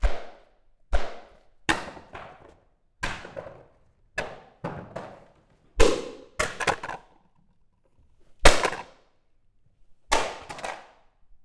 Kitchen Box Butter Falling Sound
household